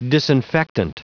Prononciation du mot disinfectant en anglais (fichier audio)
Prononciation du mot : disinfectant